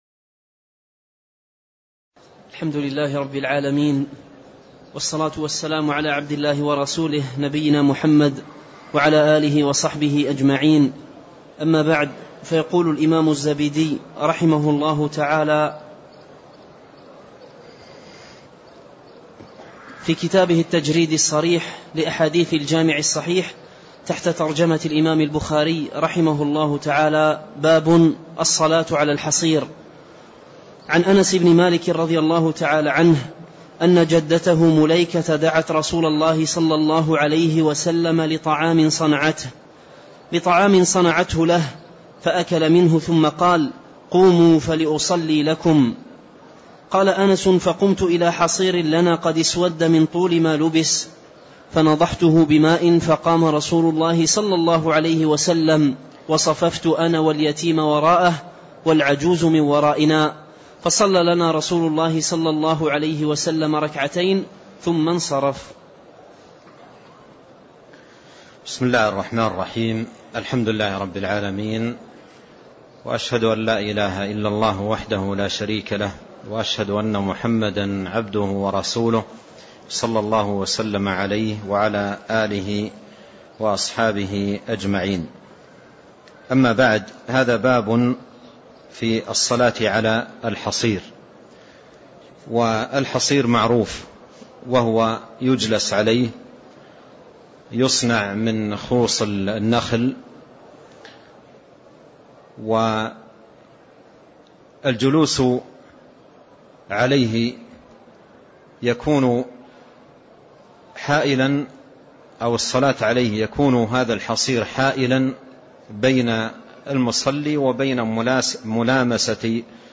تاريخ النشر ١١ جمادى الآخرة ١٤٣٣ هـ المكان: المسجد النبوي الشيخ: فضيلة الشيخ عبد الرزاق بن عبد المحسن البدر فضيلة الشيخ عبد الرزاق بن عبد المحسن البدر باب الصلاةِ عَلَى الحَصيرِ (04)